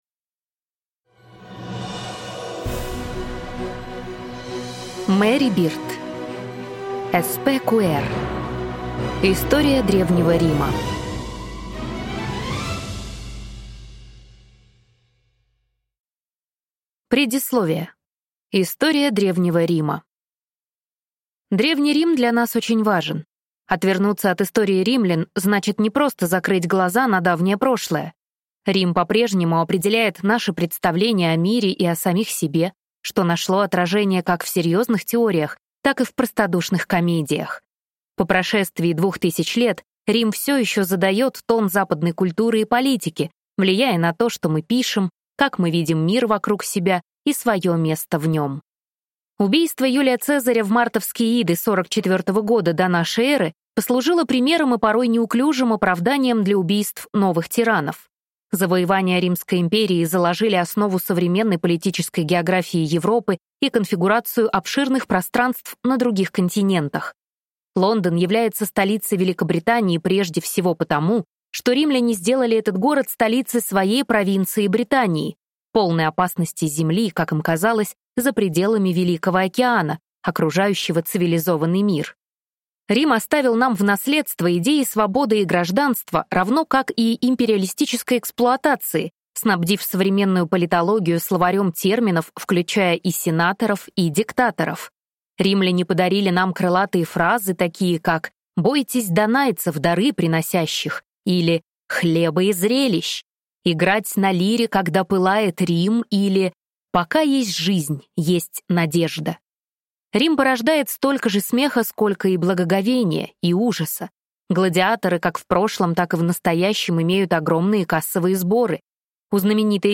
Аудиокнига SPQR. История Древнего Рима | Библиотека аудиокниг